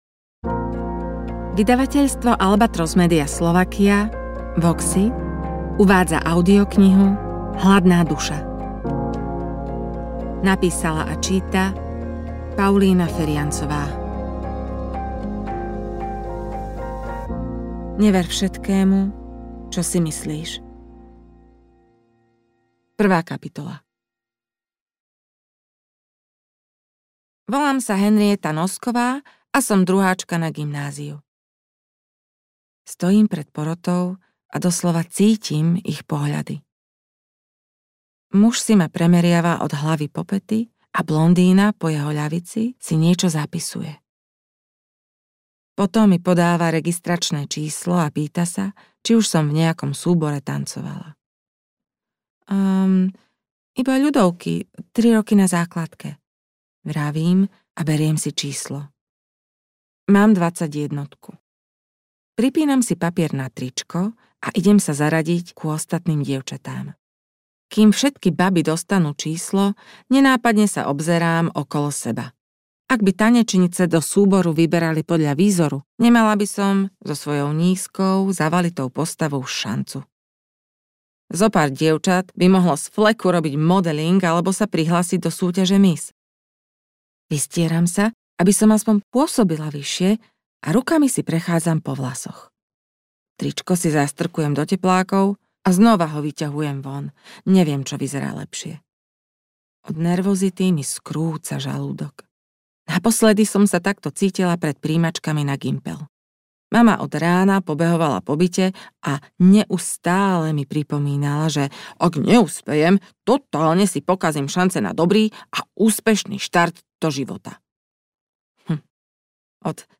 AudioKniha ke stažení, 53 x mp3, délka 12 hod. 15 min., velikost 670,3 MB, slovensky